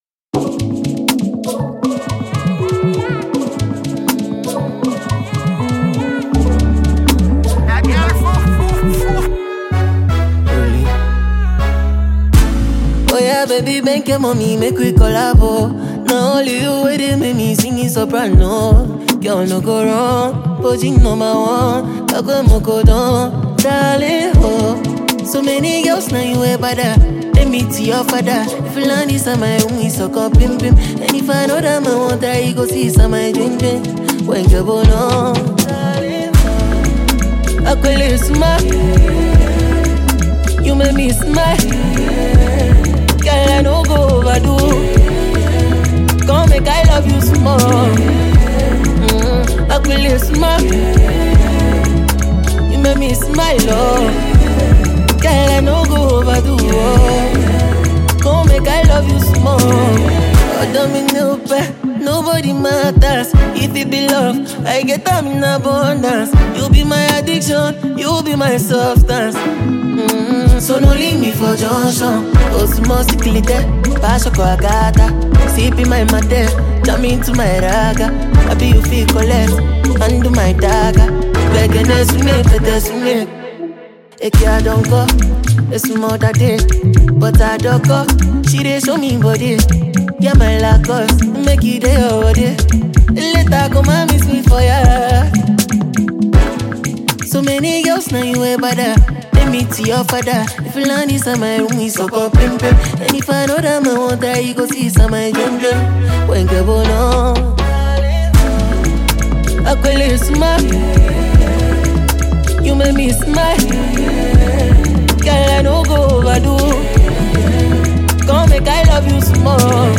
Ghana